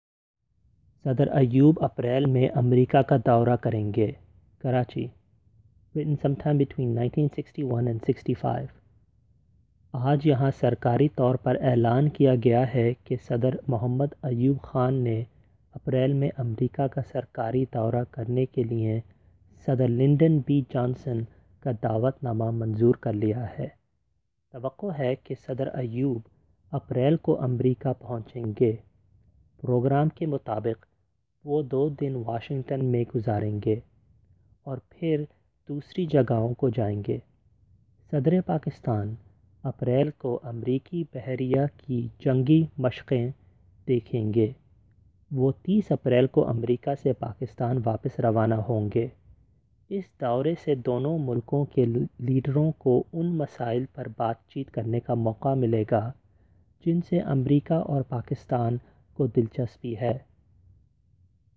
Once you feel you know the words, the second audio recording will be only in Urdu without any English translations.